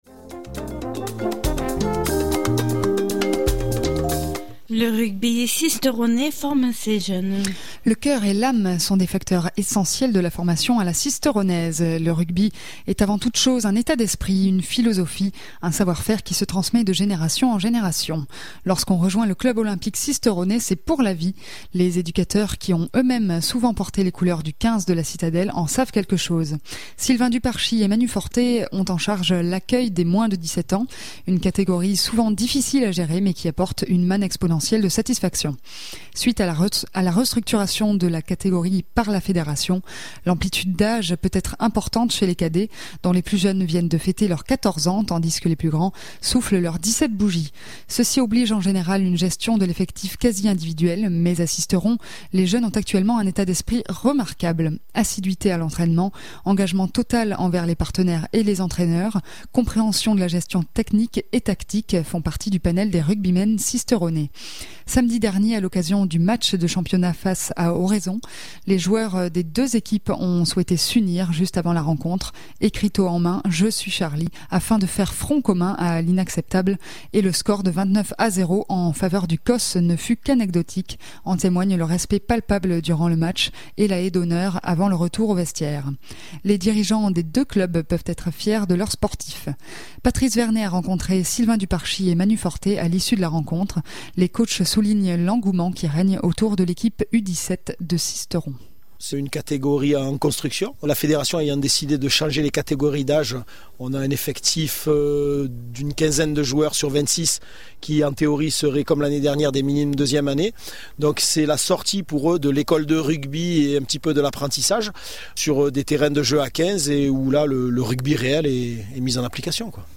les coachs soulignent l’engouement qui règne autour de l’équipe U17 de Sisteron.